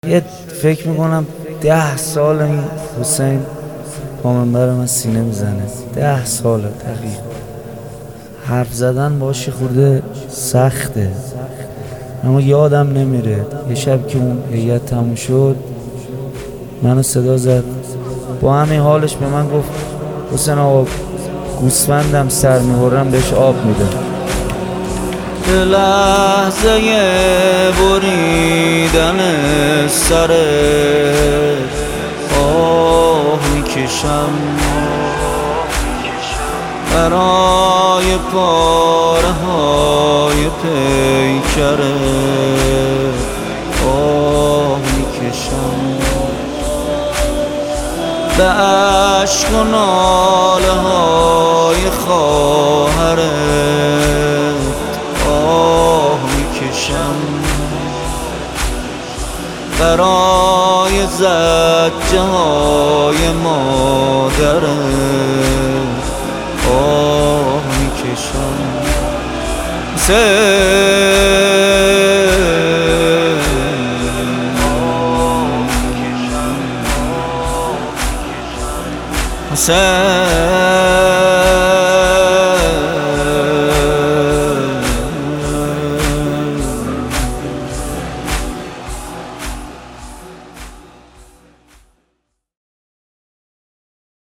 پامنبری خاص